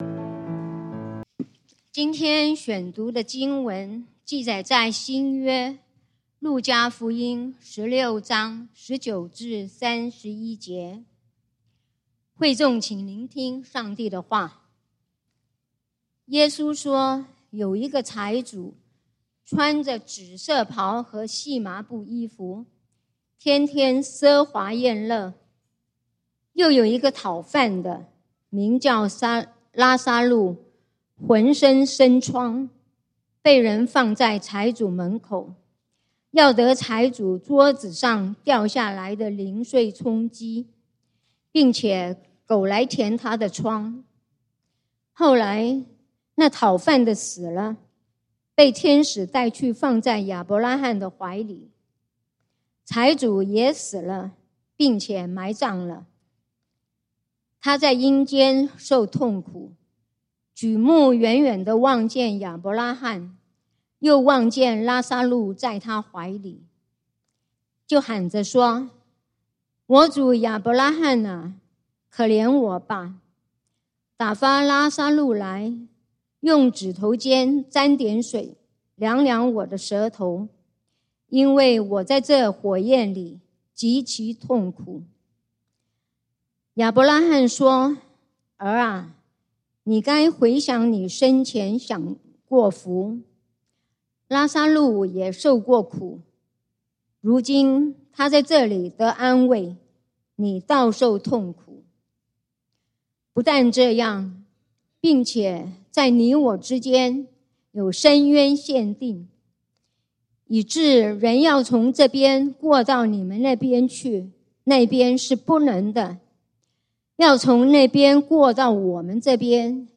9/28/2025 講道經文：路加福音 Luke 16:19-31 本週箴言：馬太福音 Matthew 7:13-14 耶穌說：「你們要進窄門。